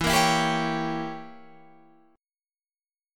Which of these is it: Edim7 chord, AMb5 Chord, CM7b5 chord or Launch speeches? Edim7 chord